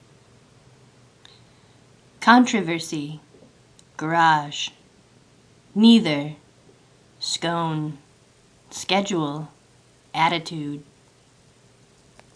San Francisco, CA. 1975, female